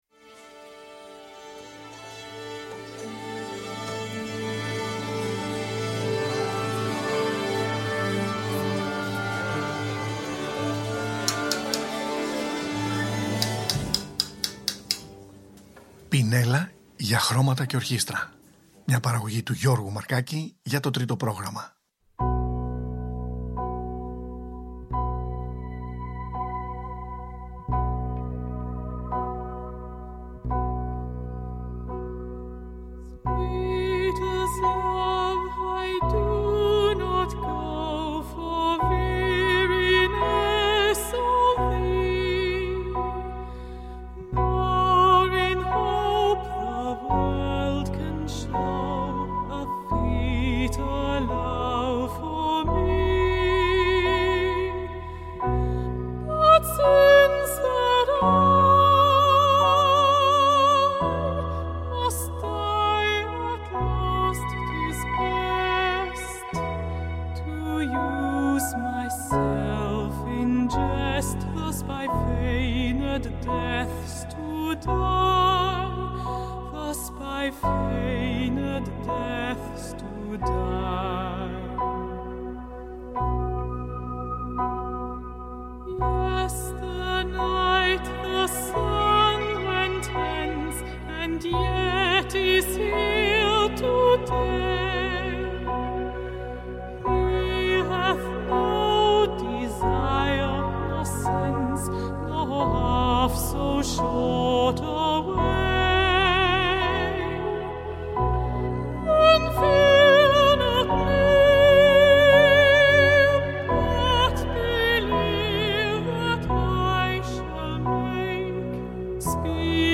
World Music
Μουσικη για τα Χριστουγεννα